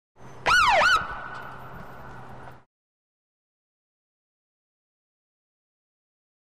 One Short Siren Bleep Close.